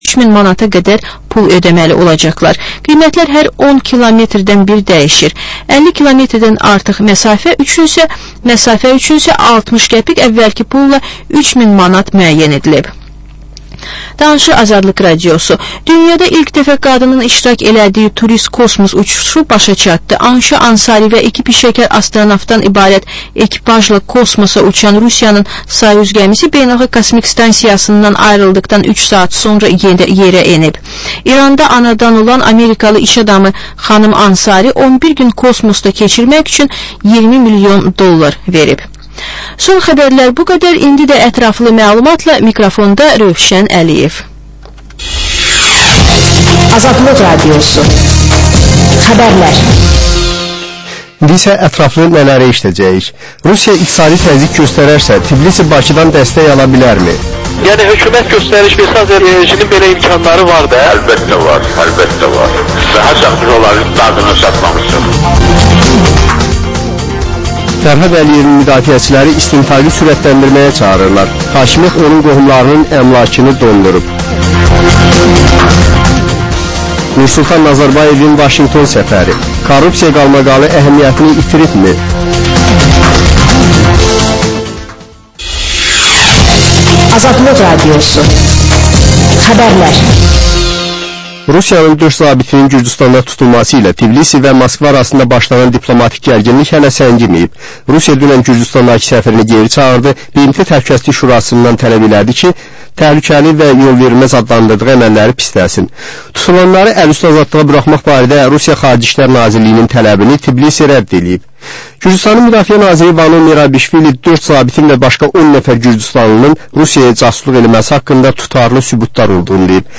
Reportaj, təhlil, müsahibə